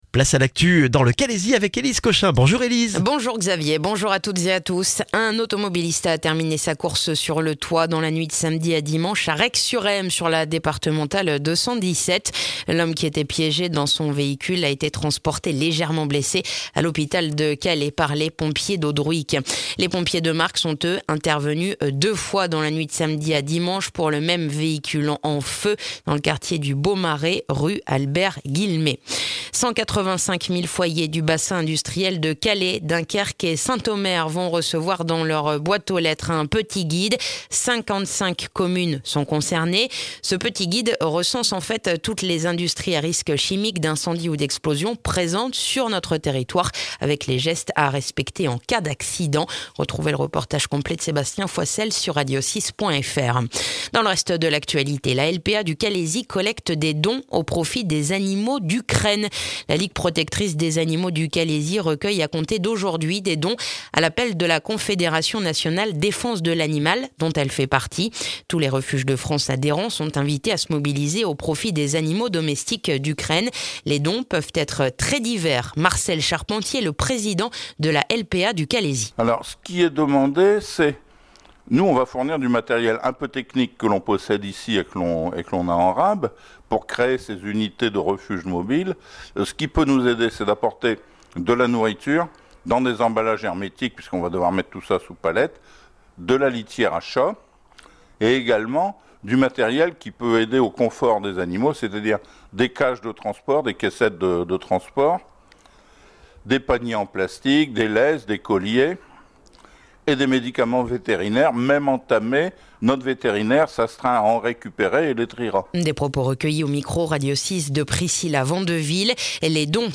Le journal du lundi 21 mars dans le calaisis